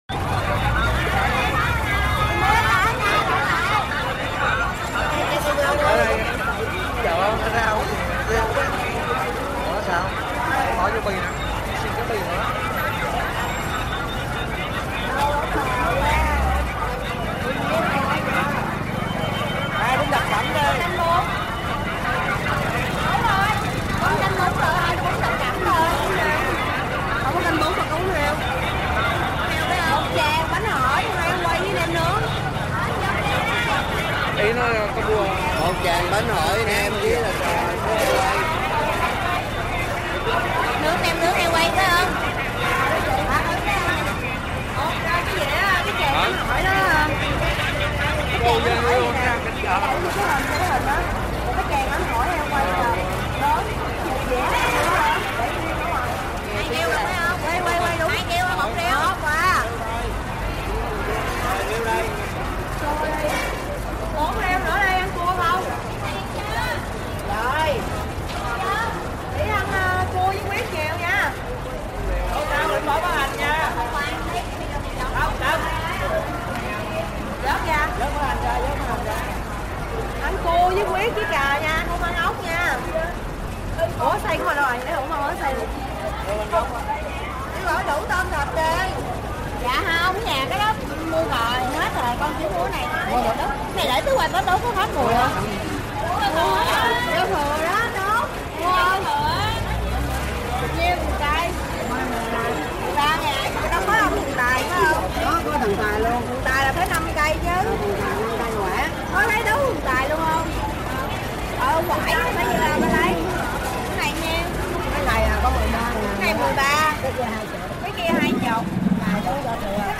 Âm thanh môi trường, tiếng Ồn ào ở chợ Việt Nam
Thể loại: Tiếng động
Description: Âm thanh môi trường của chợ Việt Nam như một bản hòa tấu hỗn hợp: tiếng ồn ào, huyên náo, xôn xao, rộn ràng vang khắp lối đi. Tiếng mặc cả, tiếng cười nói, tiếng xe máy len lỏi qua dòng người..., từng lớp âm thanh này được ghi lại rõ nét, tạo cảm giác người xem như đang đứng giữa khu chợ, cảm nhận nhịp sống đời thường đầy năng lượng.
am-thanh-moi-truong-tieng-on-ao-o-cho-viet-nam-www_tiengdong_com.mp3